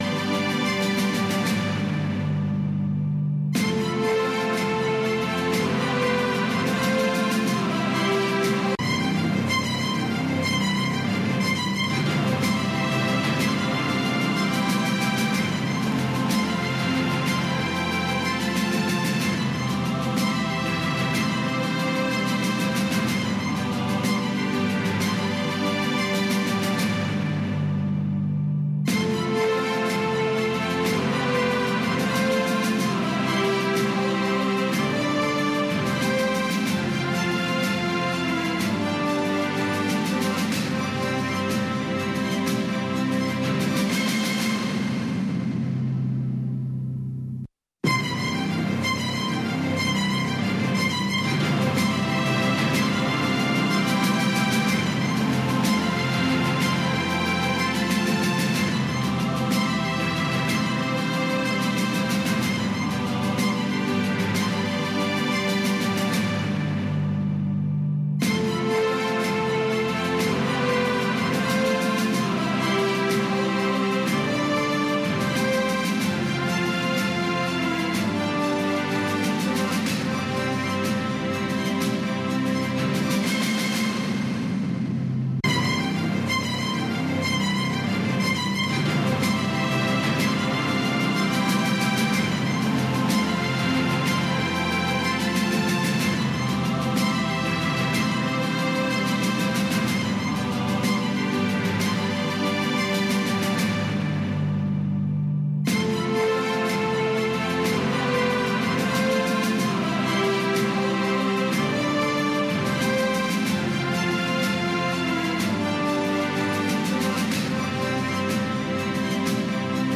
په دې خپرونه کې اوریدونکي کولی شي خپل شعر یا کوم پیغام نورو سره شریک کړي. د شپې ناوخته دا پروگرام د سټرو اوریدنکو لپاره ښائسته خبرې او سندرې هم لري.